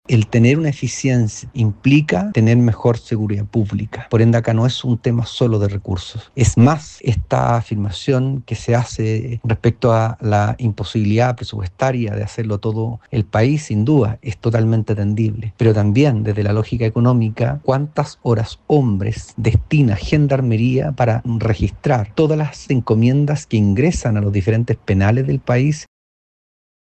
No obstante, el también exdirector de la institución Christián Alveal, se preguntó sobre cuántas horas-hombre utiliza Gendarmería para revisar todas las encomiendas que los reclusos reciben, particularmente, de ropa, muchas veces, utilizadas también para el ingreso de los elementos prohibidos.